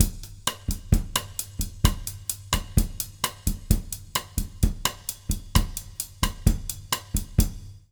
130BOSSA01-L.wav